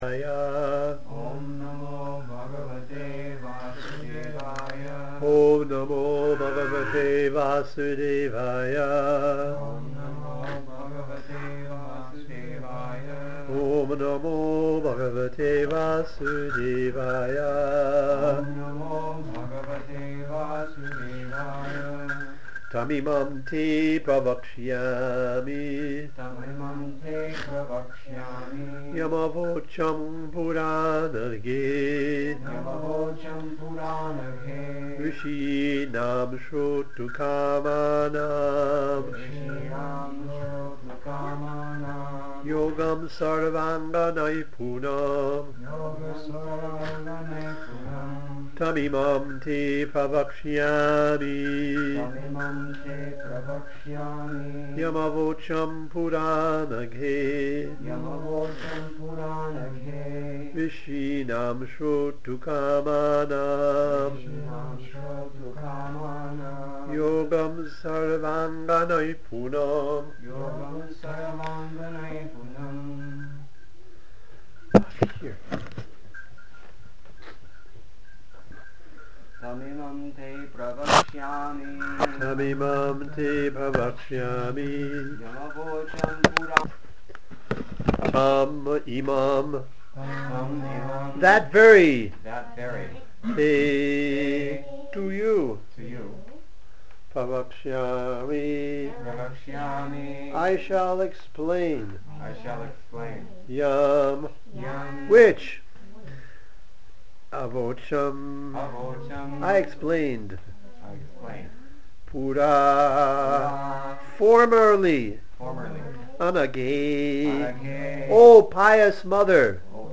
Lecture
at ISKCON Vrindavan, India